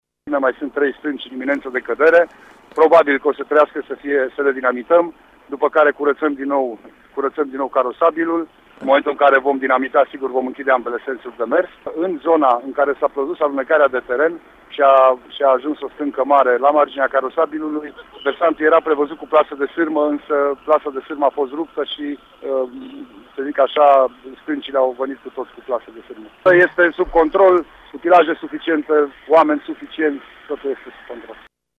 Goga a declarat pentru RTM că la această oră carosabilul a fost deblocat de rocile căzute: